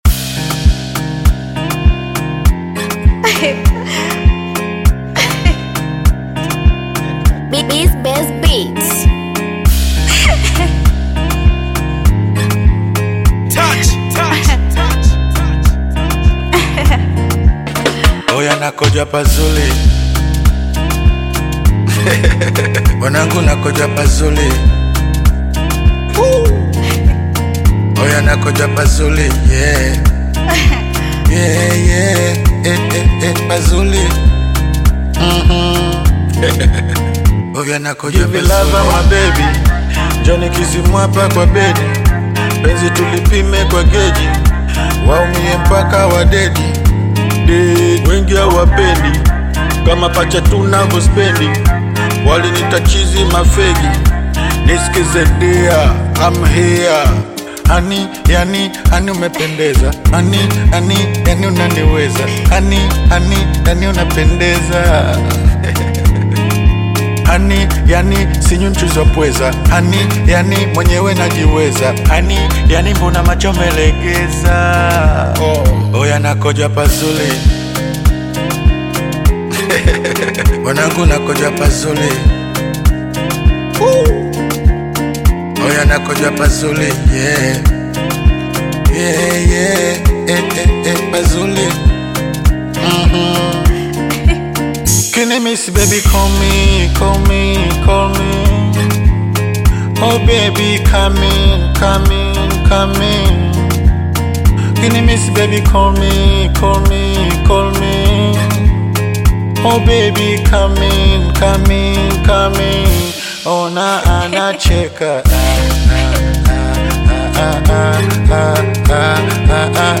vibrant and energetic music audio
blends catchy rhythms
upbeat and dynamic vibes